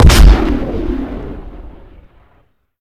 Rifle1.ogg